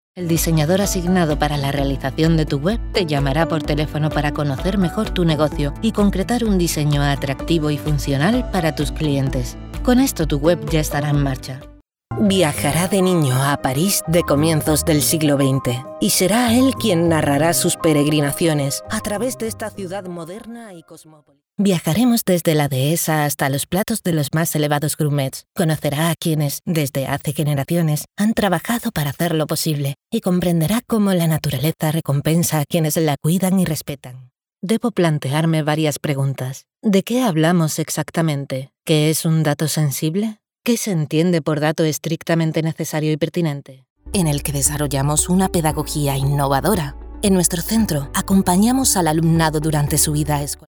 Soy una locutora espaĂ±ola con acento espaĂ±ol castellano neutro. Voz femenina comercial, publicitaria, natural, convincente, elegante, juvenil, adulta.
Sprechprobe: Industrie (Muttersprache):
I have my own professional soundproof studio.